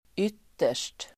Uttal: ['yt:er_st]